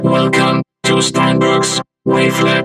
经过Vokator处理后的人声：
vocoder1.mp3